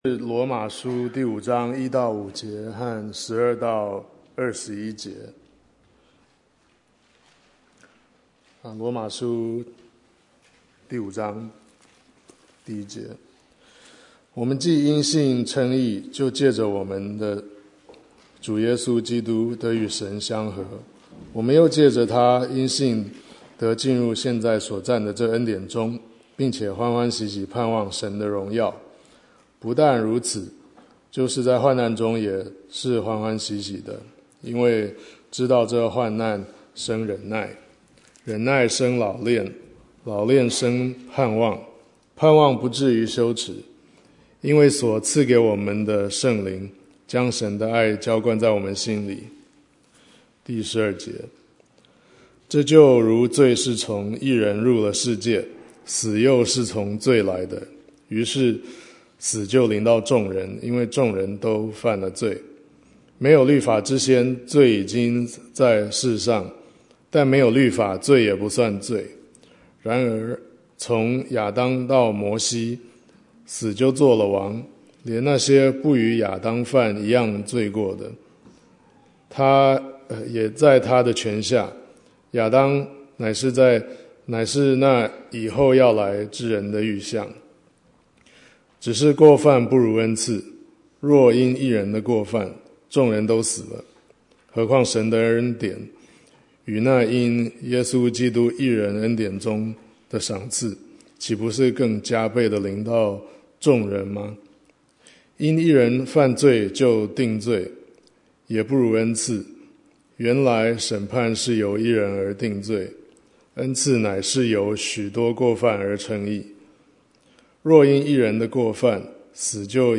Bible Text: 羅馬書5:1-5, 12-21 | Preacher